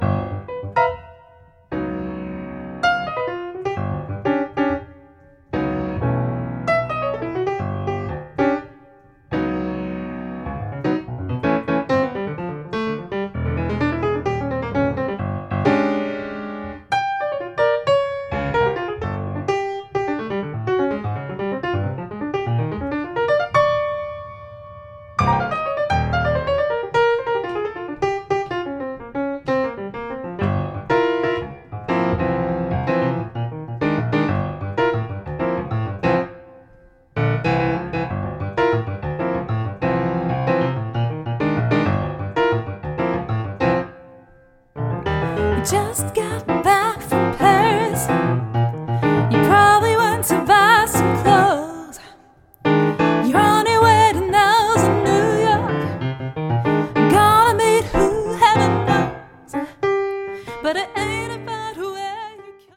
vocals
piano